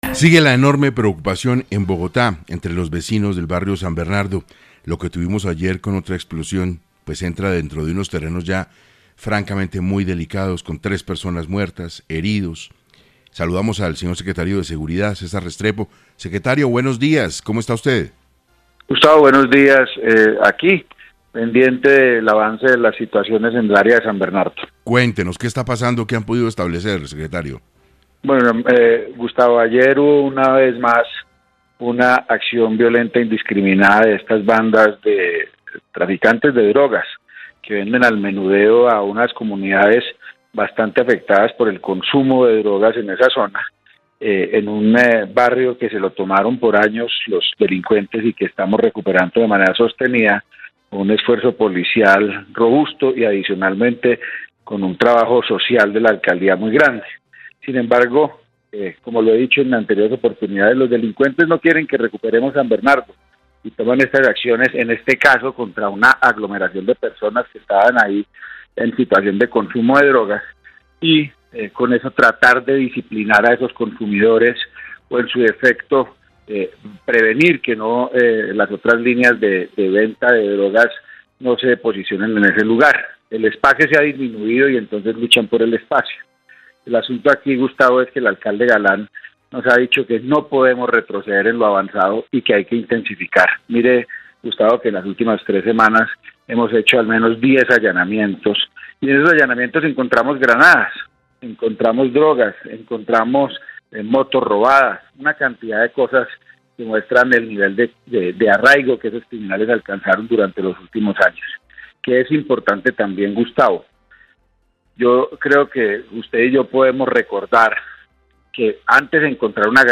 El secretario de Seguridad, César Restrepo habló en 6AM sobre los indicios detrás del atentado.